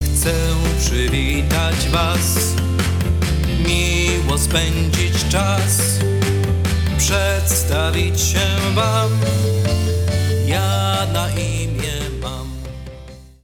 Wesoła i rytmiczna piosenka na rozpoczęcie zajęć